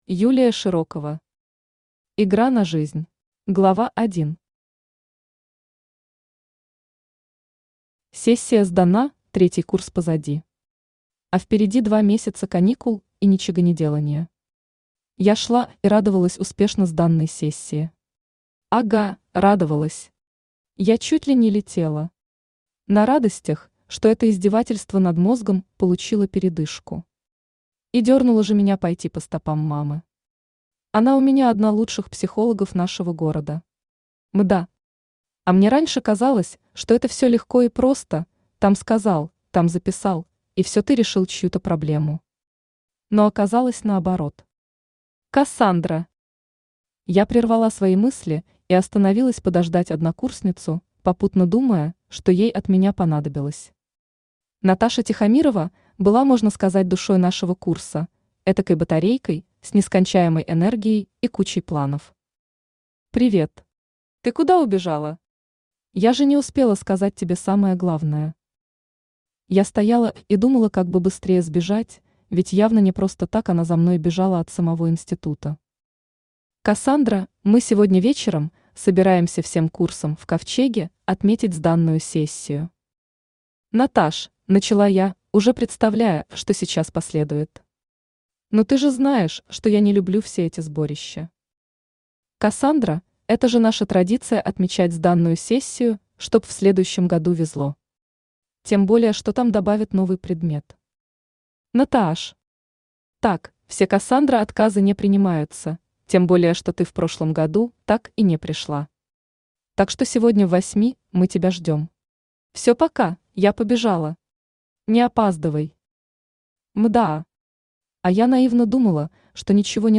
Аудиокнига Игра на жизнь | Библиотека аудиокниг
Aудиокнига Игра на жизнь Автор Юлия Широкова Читает аудиокнигу Авточтец ЛитРес.